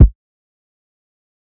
flat kick.wav